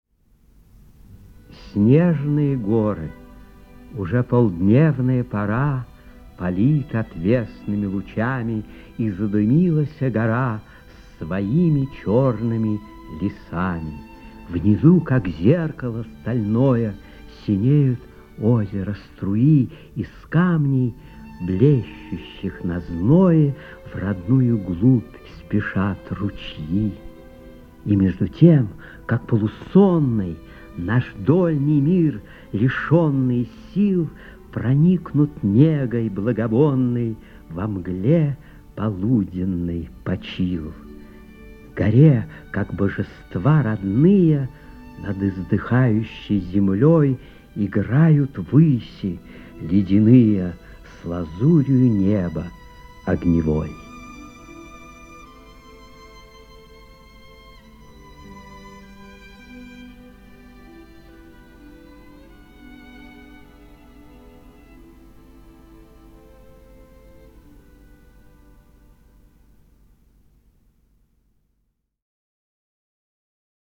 2. «Ф. Тютчев – Снежные горы (читает Владимир Зельдин)» /
Tyutchev-Snezhnye-gory-chitaet-Vladimir-Zeldin-stih-club-ru.mp3